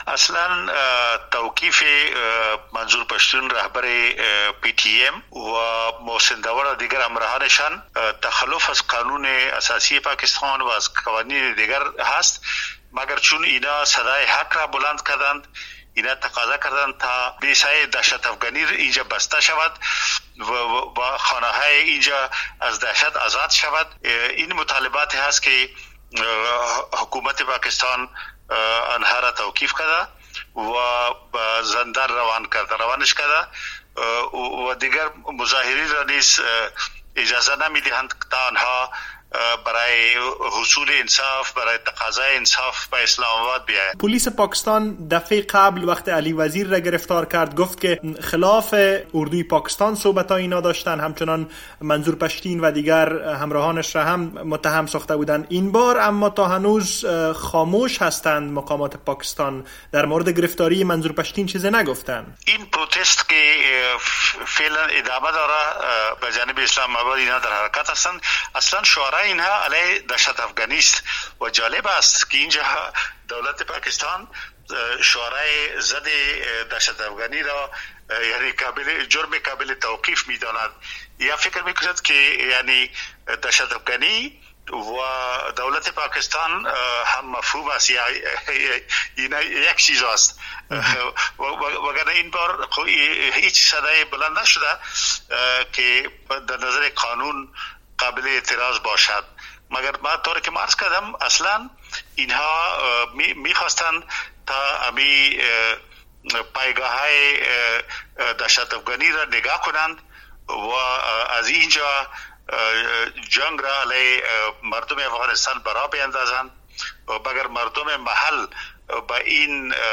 مصاحبه - صدا
گفتگو با افراسیاب ختک